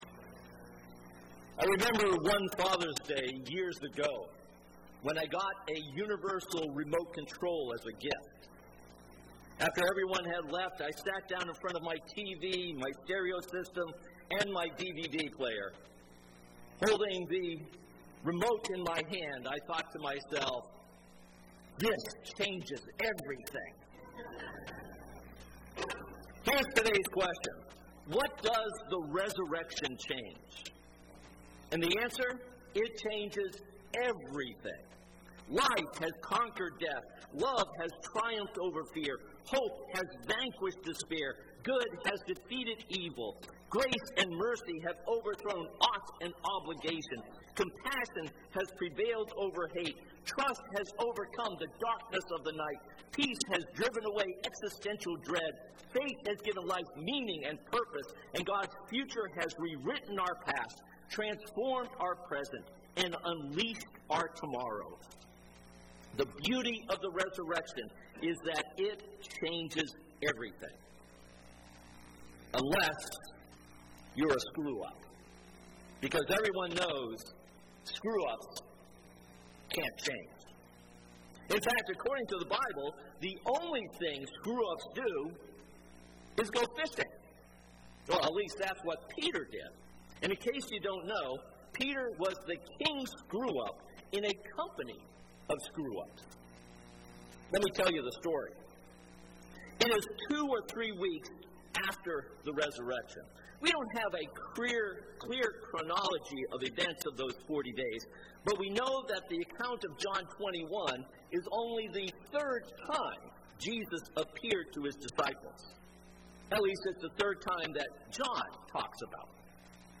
This Easter Sunday sermon is based on John 21:2-18.